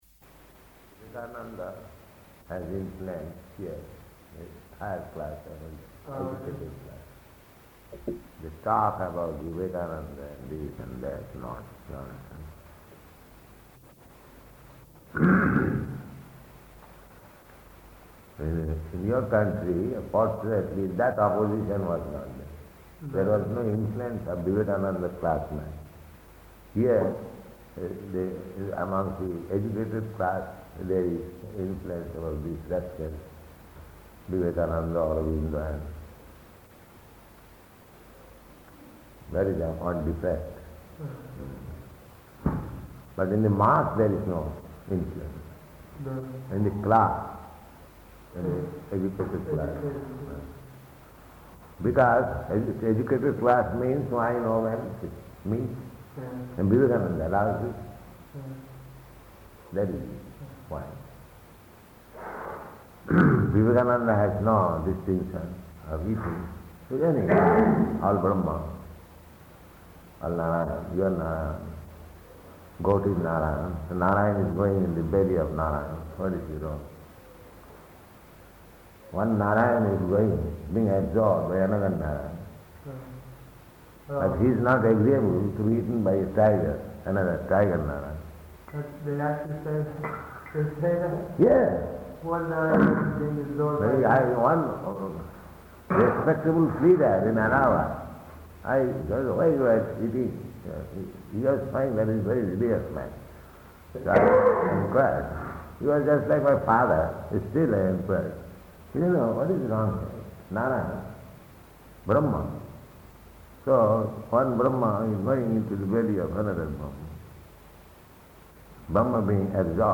Room Conversation
Room Conversation --:-- --:-- Type: Conversation Dated: January 17th 1971 Location: Allahabad Audio file: 710117R1-ALLAHABAD.mp3 Prabhupāda: Vivekananda has influence here in higher class, amongst educated class.